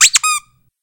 HitsoundSqueak.ogg